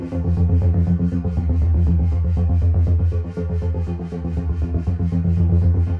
Index of /musicradar/dystopian-drone-samples/Tempo Loops/120bpm
DD_TempoDroneE_120-F.wav